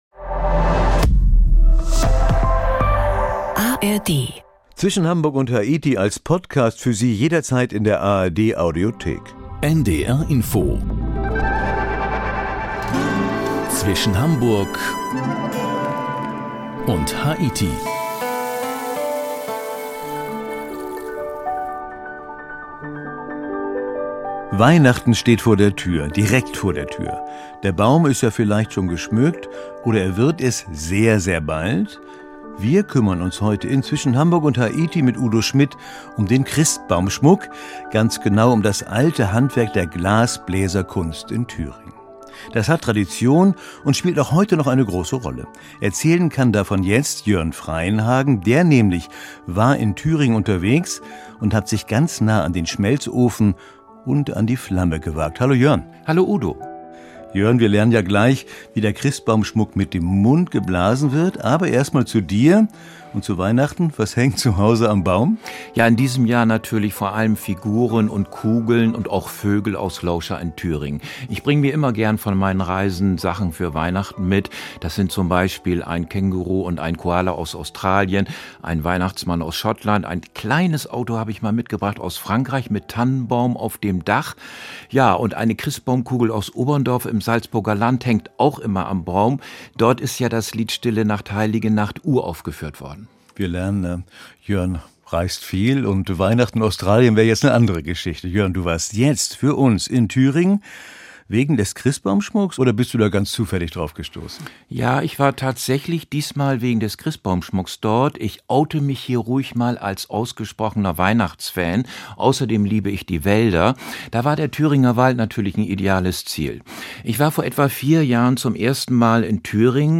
Mit dem Mikrofon rund um die Welt.